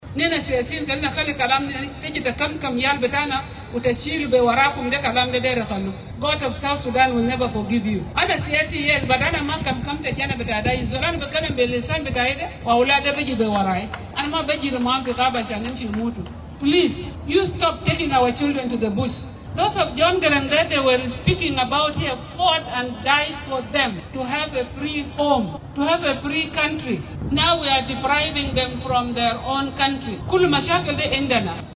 Speaking at a wedding in Juba on Saturday, April 18, 2026, the Vice President told leaders to prioritize the safety of youth over mobilization for politics or the military.